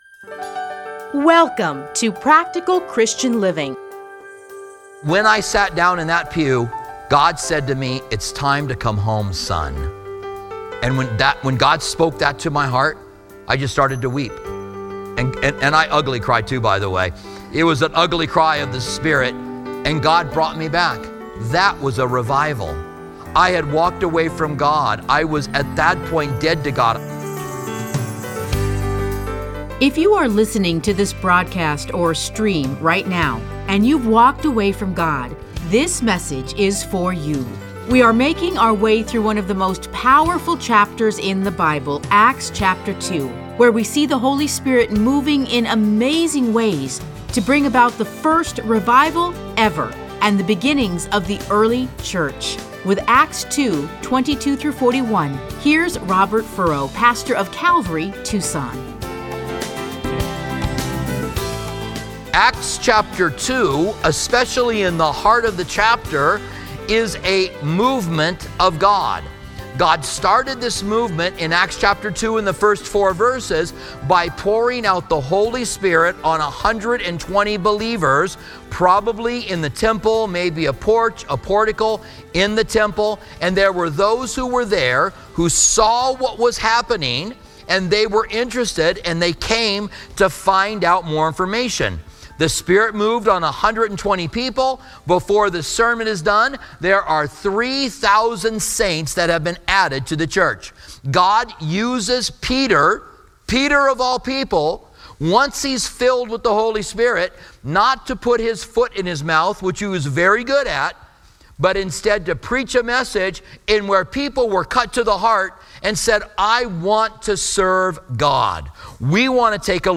Listen to a teaching from Acts 2:22-41.